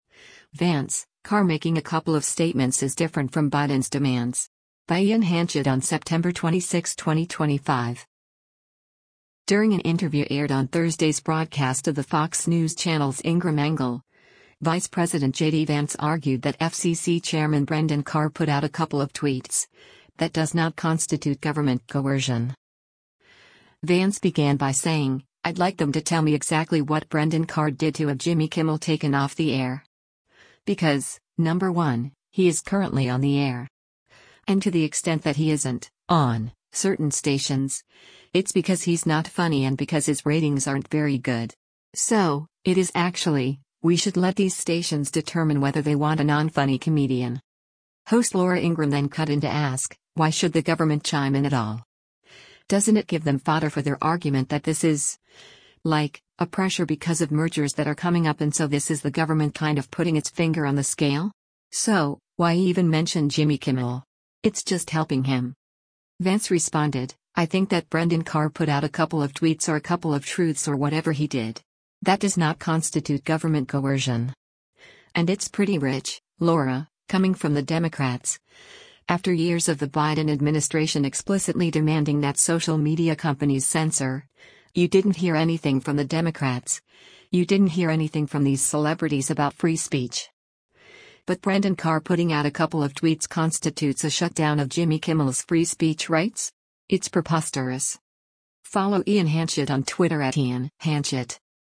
During an interview aired on Thursday’s broadcast of the Fox News Channel’s “Ingraham Angle,” Vice President JD Vance argued that FCC Chairman “Brendan Carr put out a couple of tweets…That does not constitute government coercion.”